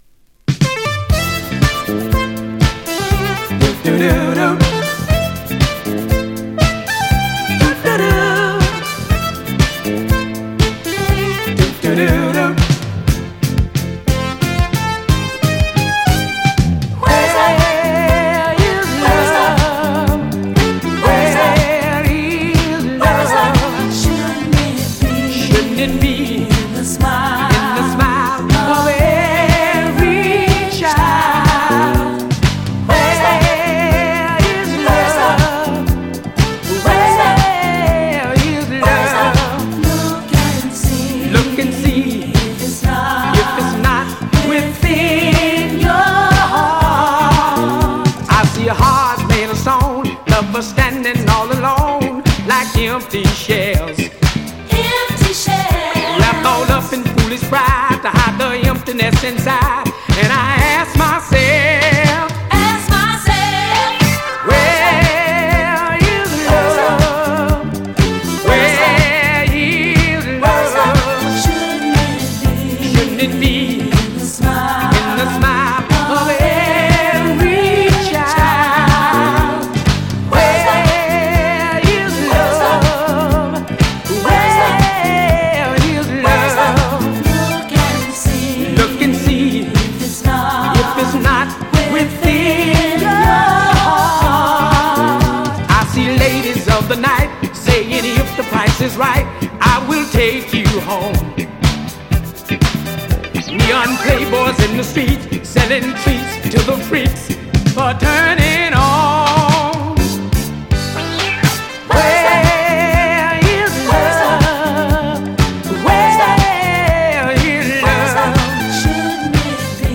SOUL
80's SOUTHERN SOUL !!